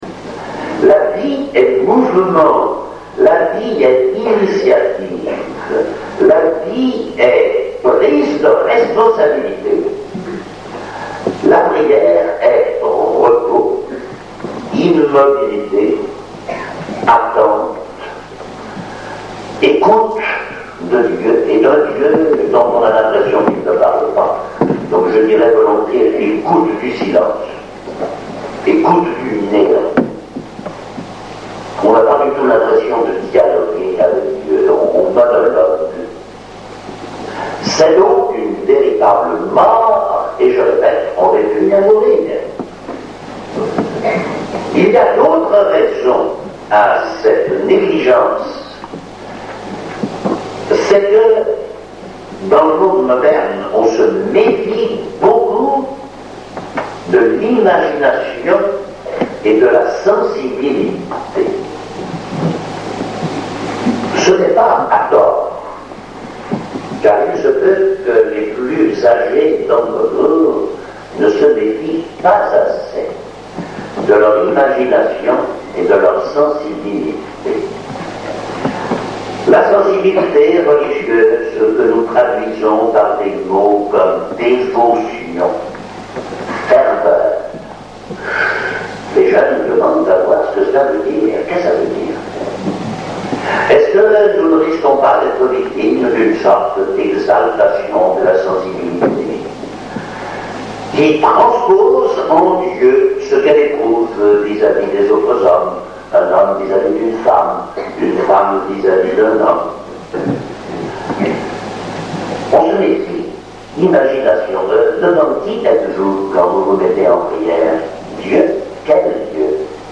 Extraits d’une conférence